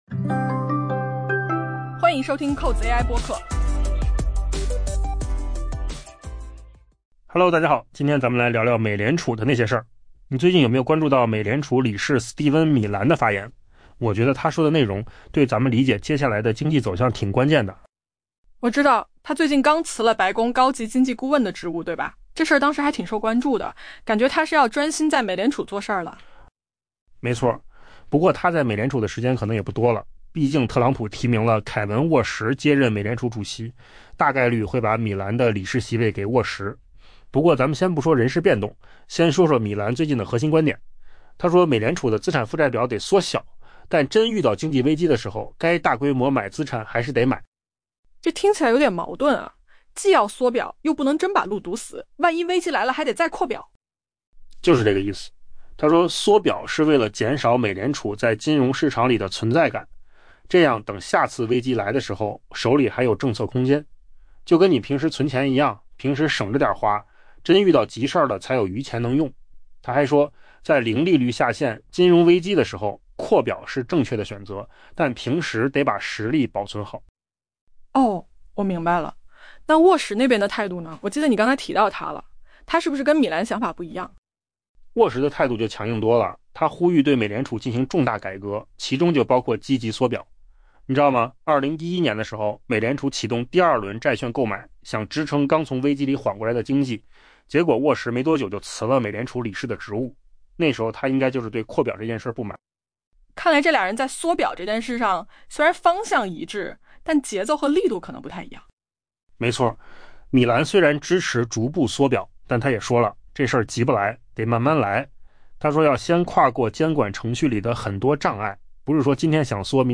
AI播客：换个方式听新闻 下载mp3 音频由扣子空间生成 美联储理事斯蒂芬·米兰（Stephen Miran）表示， 美联储的资产负债表需要缩小，但这不应阻止决策者在经济危机中选择进行大规模资产购买。